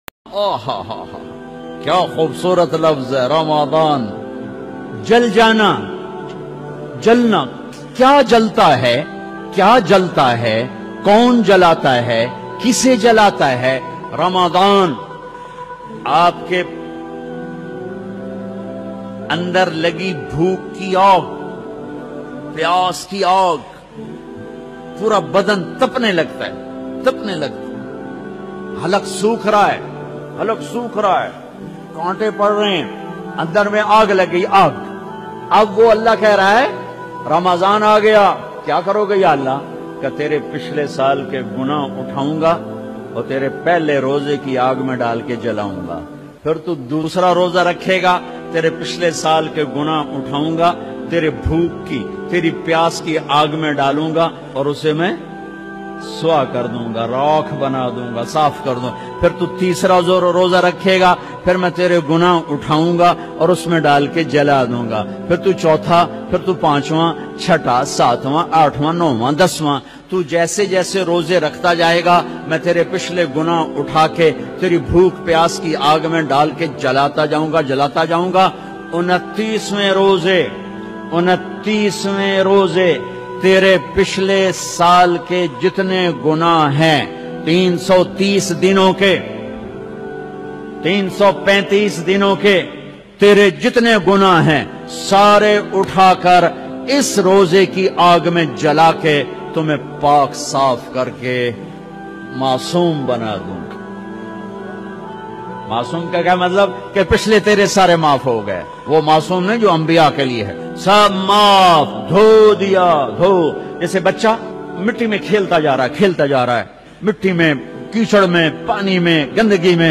Ramzan Emotional Maulana Tariq Jameel latest bayan
Molana-tariq-jameel-emotional-bayan-ramzan.mp3